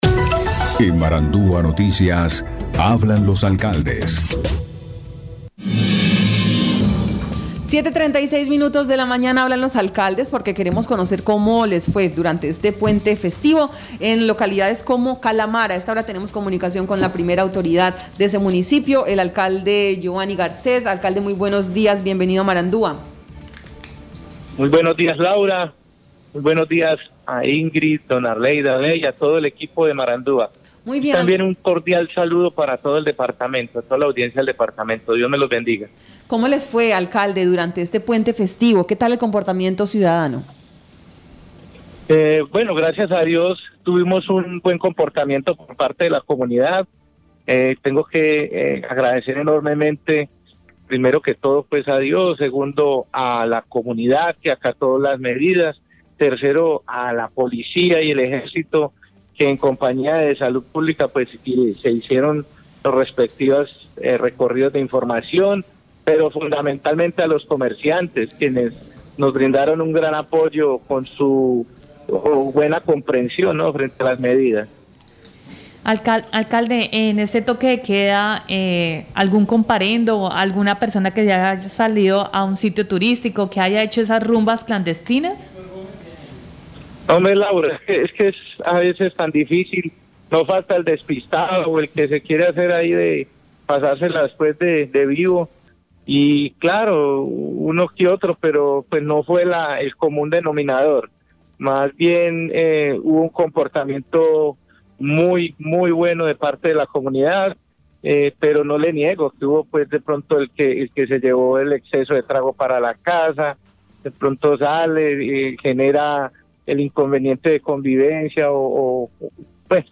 Escuche a Giovanny Garcés, alcalde de Calamar, Guaviare.